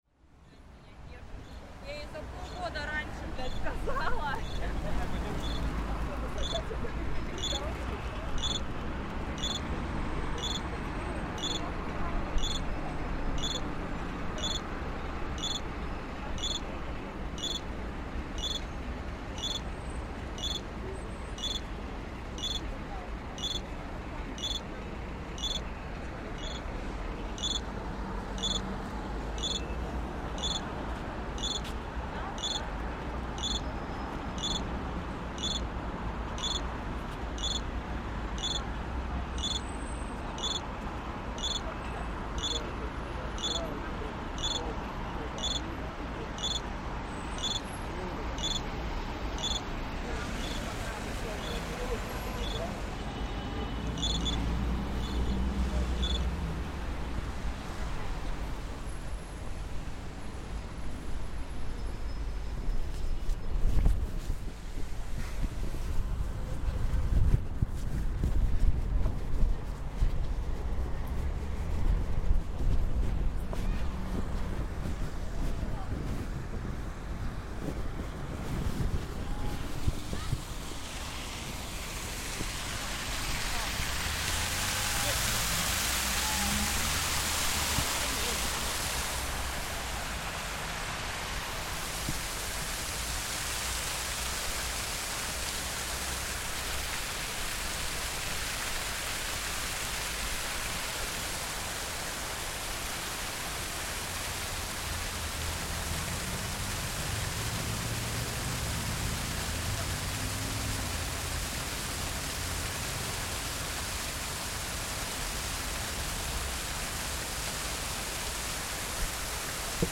A soundscape from the major road running across the front of the Bolshoi Theatre, Moscow, that really demonstrates the busy-ness of central Moscow.
You can make out the water fountains outside the front of the theatre, but these are soon drowned out by the sounds of traffic, signals and a dramatic motorcycle departure.